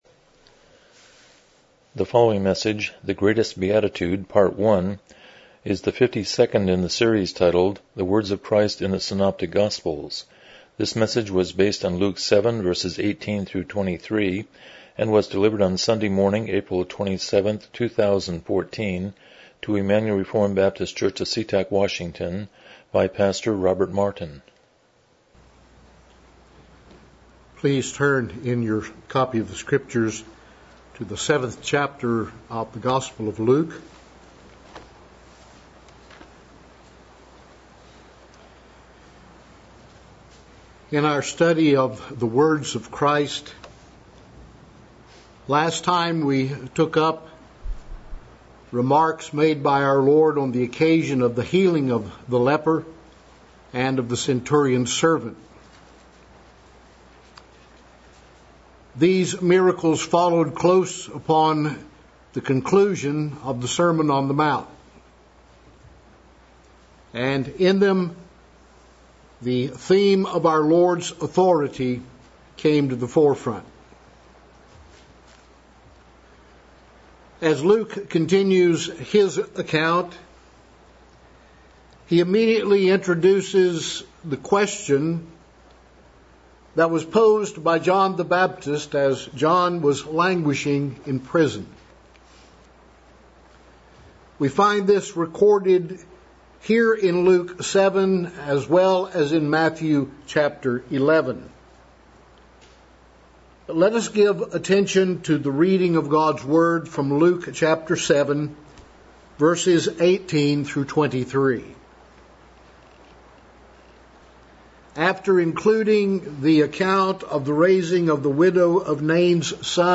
Passage: Luke 7:18-23 Service Type: Morning Worship